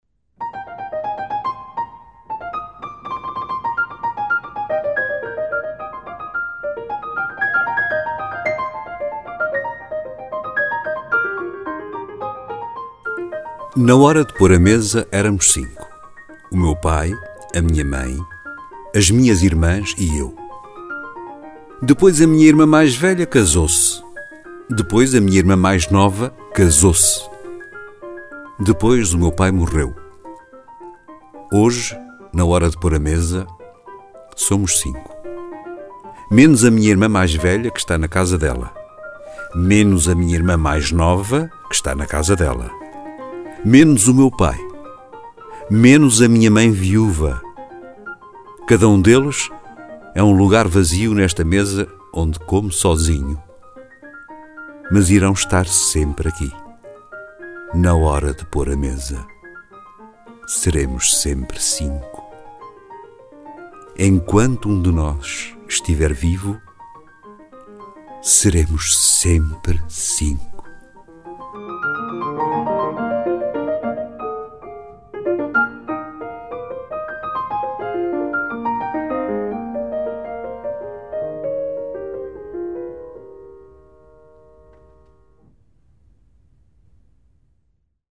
Lecture du poème